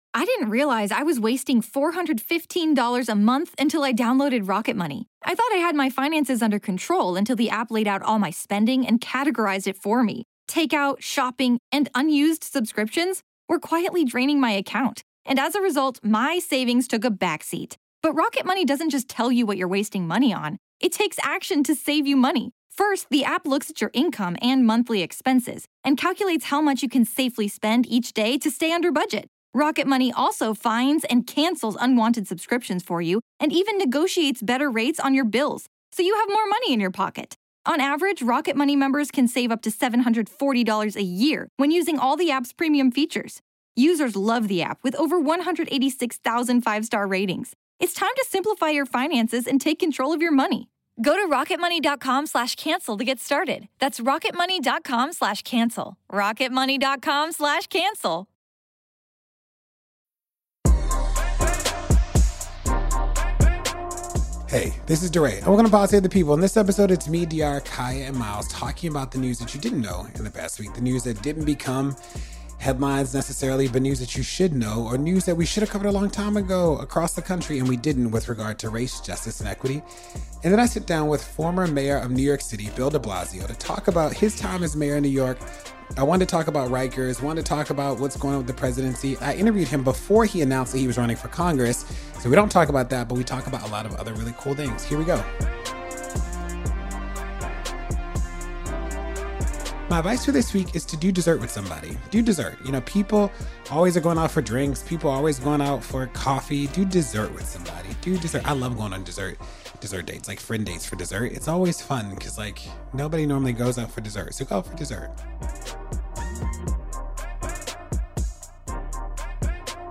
DeRay interviews former mayor of New York Bill DeBlasio about his current moves, advice for the current administration, and more.